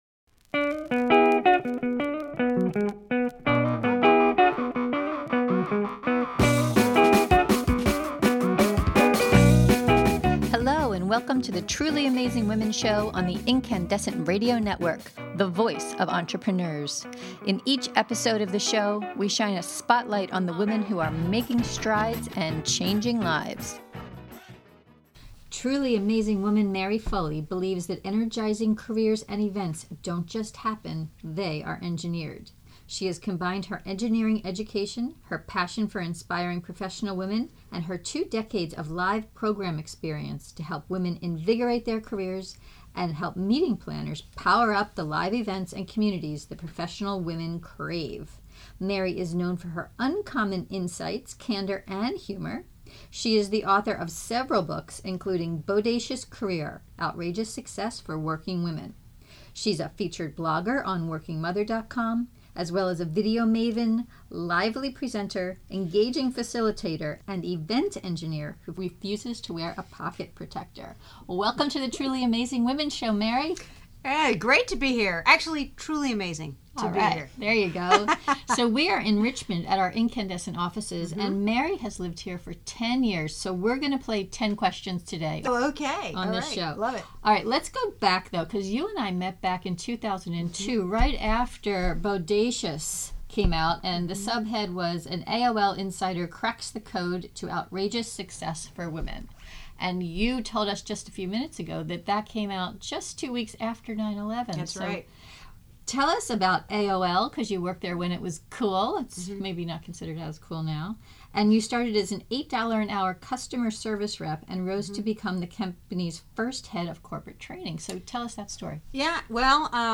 Truly Amazing Women Radio Show